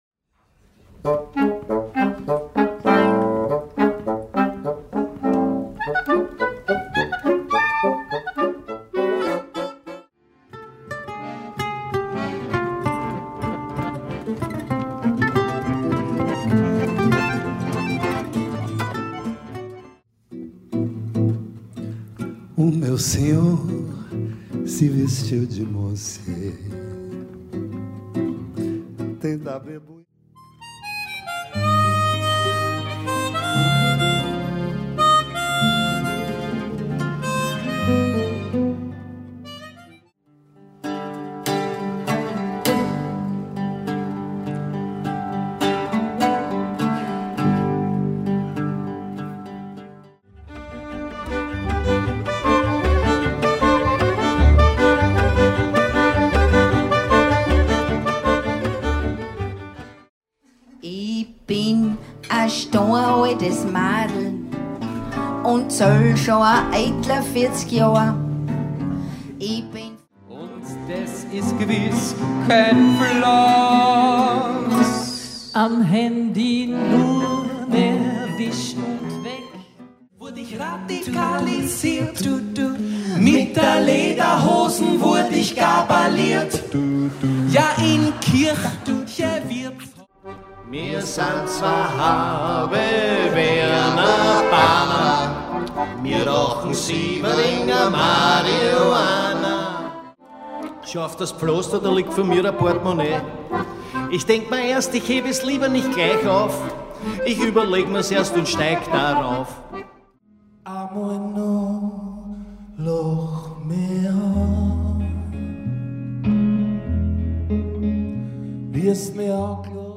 Hier können Sie einen musikalischen Schnelldurchlauf hören!